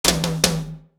ROOM TOM1C.wav